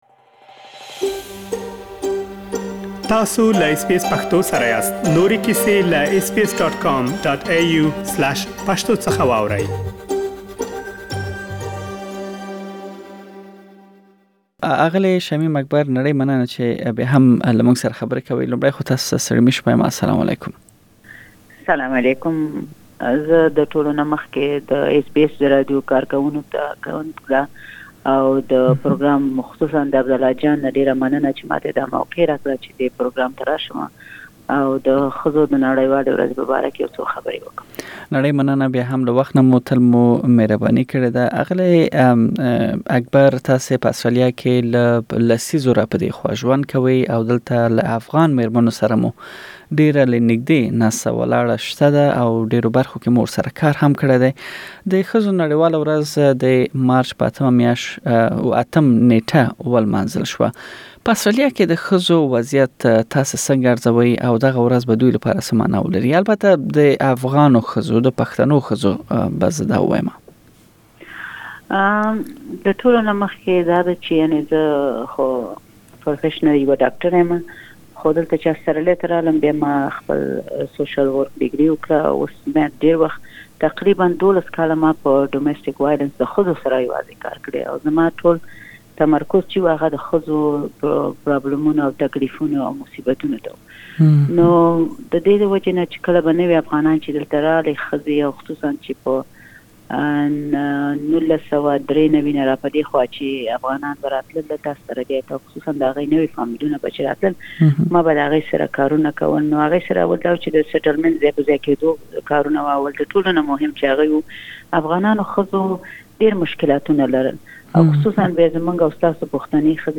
بشپړه مرکه دلته اوريدلی شئ.